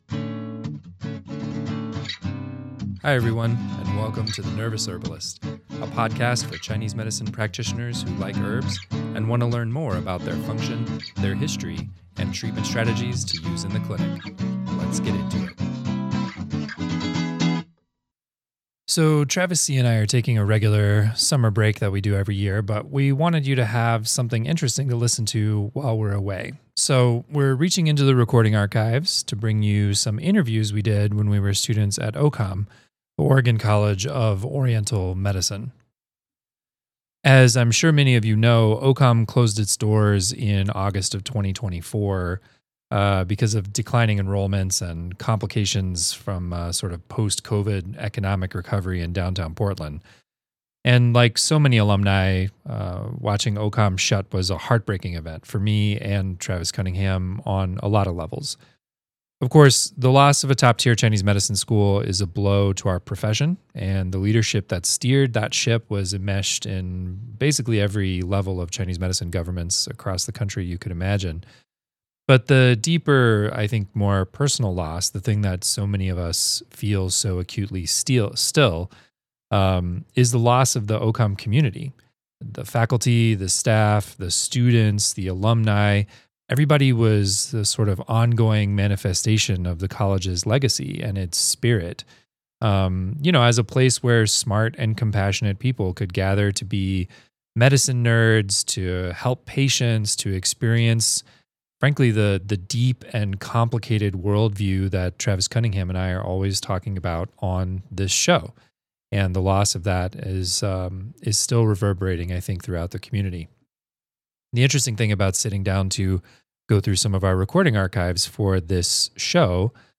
These archival podcasts talk with faculty at the Oregon College of Oriental Medicine circa 2015 about a whole host of Chinese Medicine Topics.